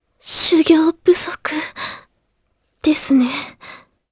Laias-Vox_Dead_jp.wav